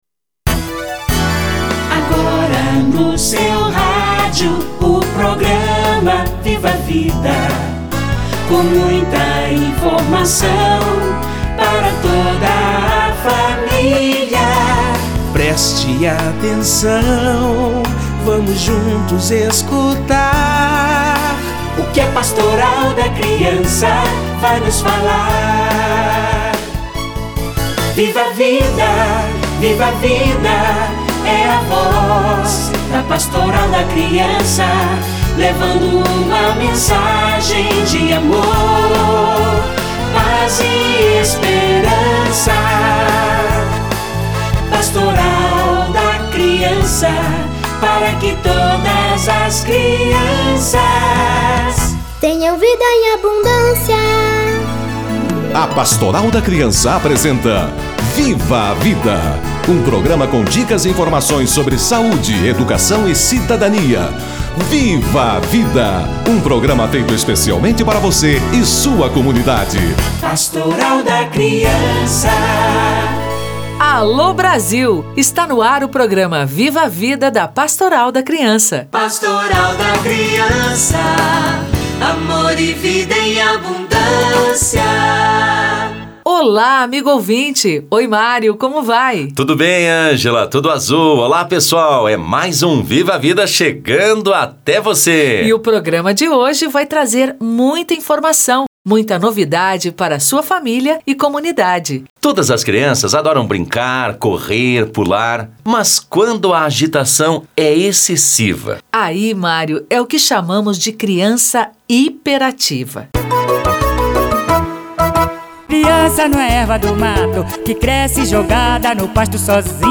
Crianças hiperativas - Entrevista